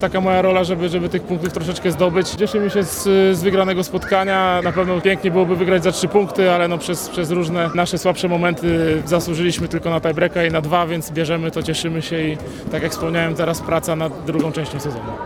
A teraz przed nami praca nad drugą częścią sezonu – powiedział po meczu dwukrotny mistrz świata.